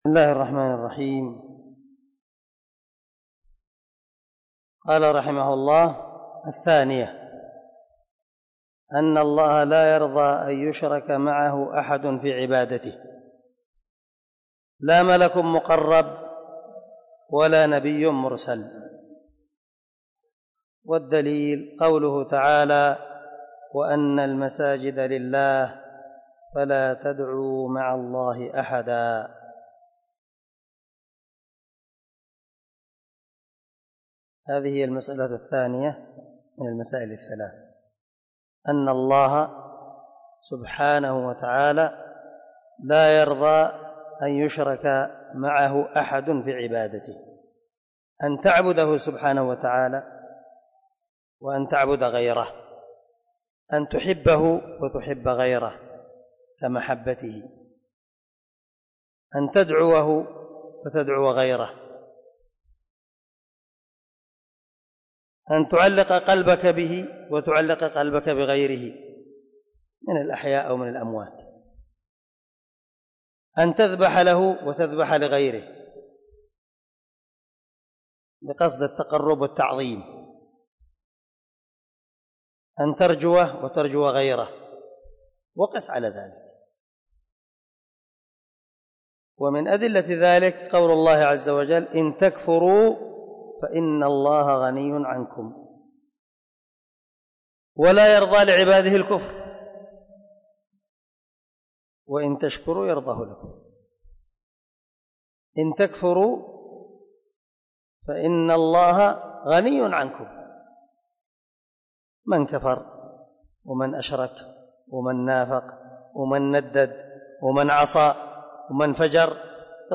🔊 الدرس 5 من شرح الأصول الثلاثة
الدرس-5-المسألة-الثانية-من-المسائل-الثلاث.mp3